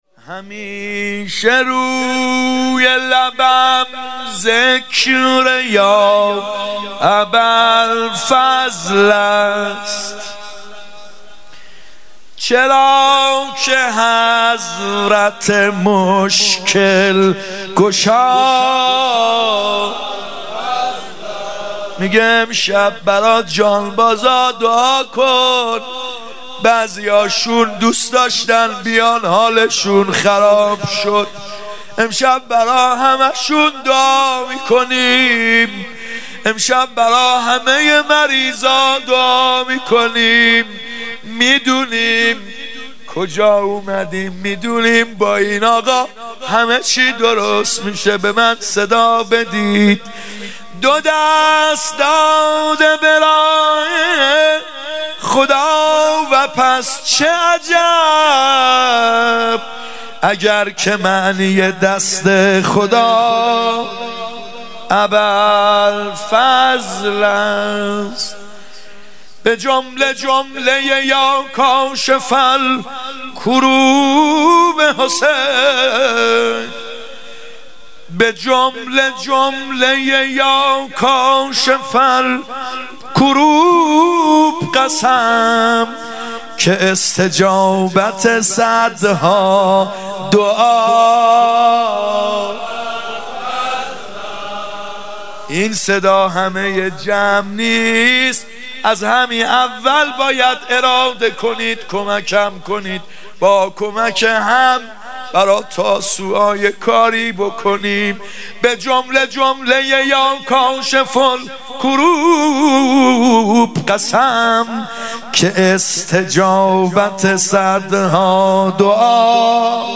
محرم 96 شب نهم واحد ( ای ساقی لب تشنگان